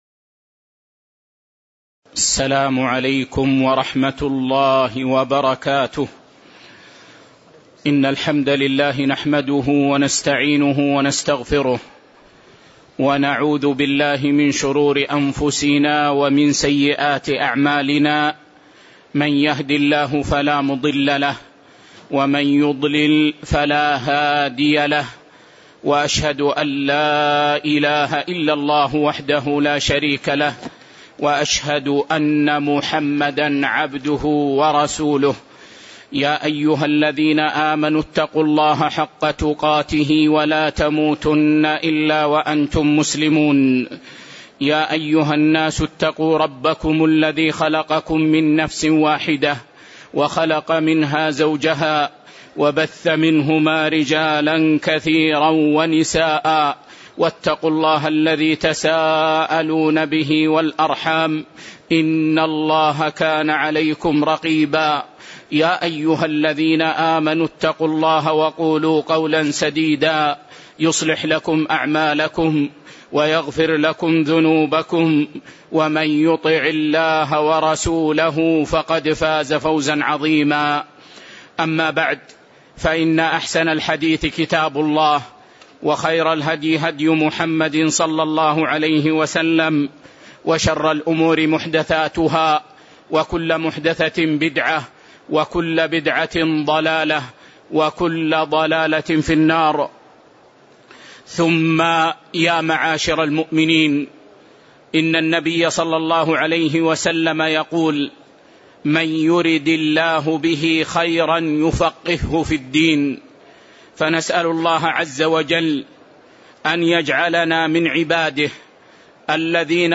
تاريخ النشر ١٦ ربيع الأول ١٤٣٨ هـ المكان: المسجد النبوي الشيخ